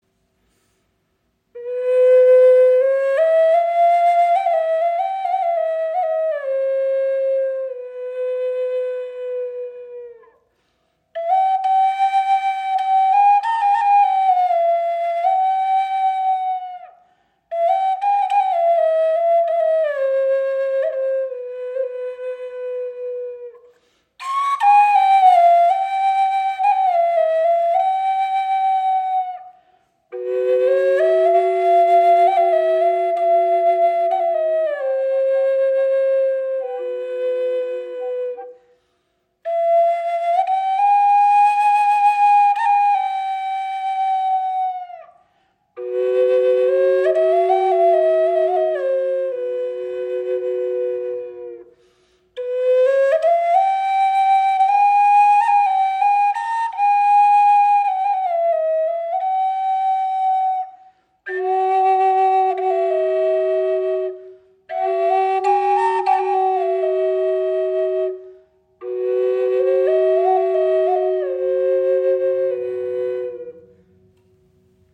Doppelflöte in C & G - Hijaz - 432 Hz im Raven-Spirit WebShop • Raven Spirit
Klangbeispiel
Diese wundervolle Doppelflöte ist auf C und G in Hijaz gestimmt und erzeugt einen warmen, tragenden Klang. Sie schenkt Dir ein wundervolles Fibrato, kann als Soloinstrument gespielt werden oder als weiche Untermahlung Deiner Musik.